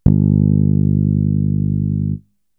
Bass (20).wav